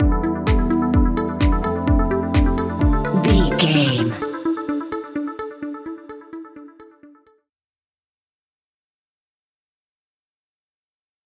Aeolian/Minor
dark
futuristic
groovy
synthesiser
drum machine
house
electro house
synth leads
synth bass